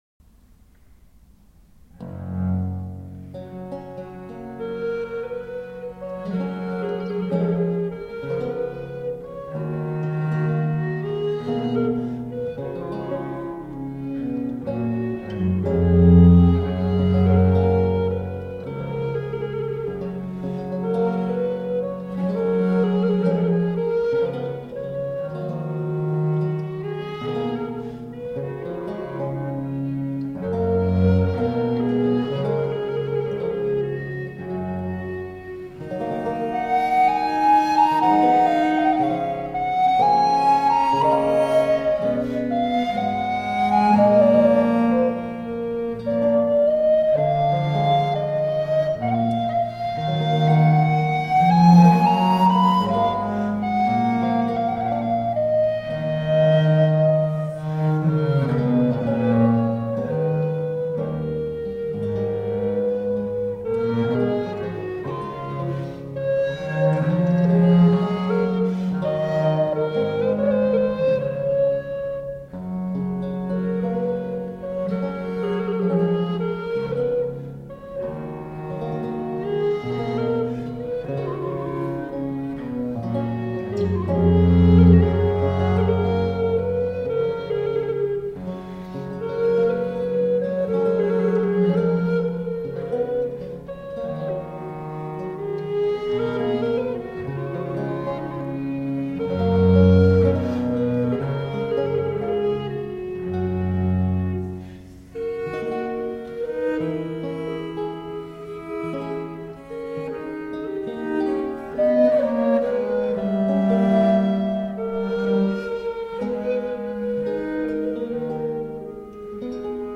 Reflective, historically-informed performance on the lute.
Recorder, Theorbo, Viola da Gamba